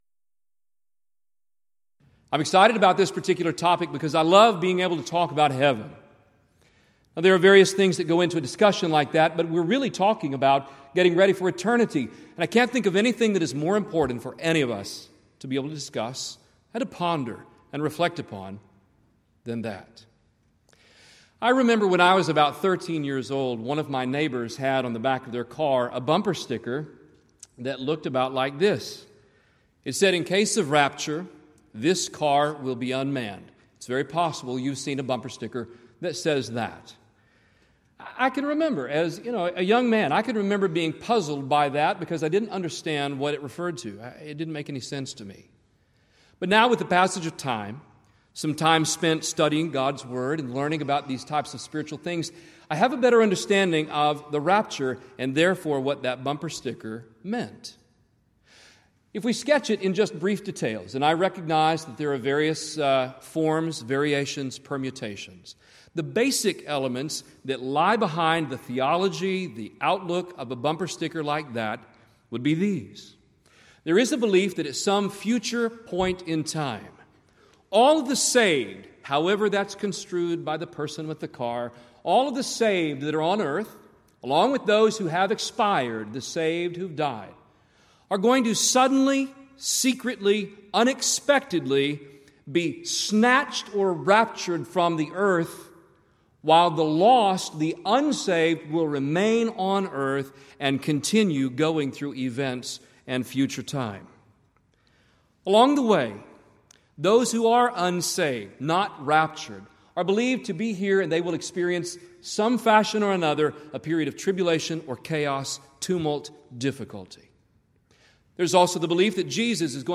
Alternate File Link File Details: Series: Southwest Lectures Event: 32nd Annual Southwest Lectures Theme/Title: Why Do We...
If you would like to order audio or video copies of this lecture, please contact our office and reference asset: 2013Southwest22 Report Problems